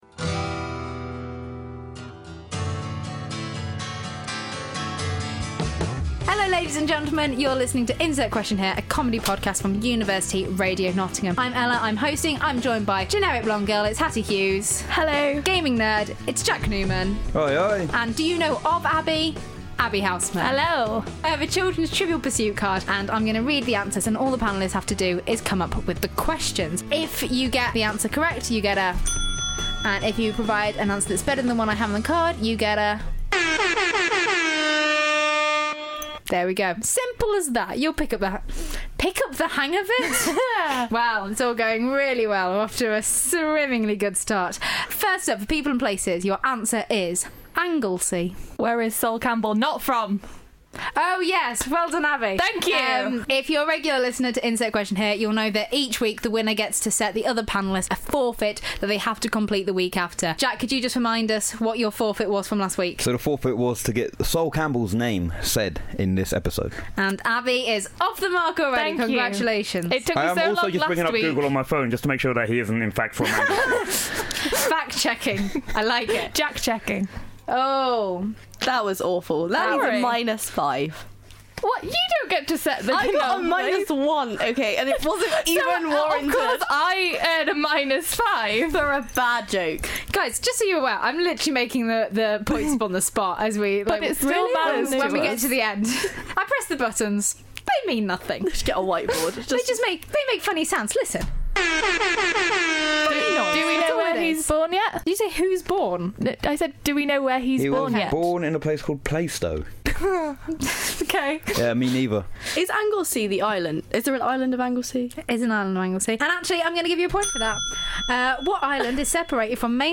4 students. 1 pack of children's Trivial Pursuit cards.